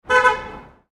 Brief Car Horn Sound Effect
Description: Brief car horn sound effect. A car honks briefly on a city street. A short warning sound from a car horn in traffic. Street sounds.
Brief-car-horn-sound-effect.mp3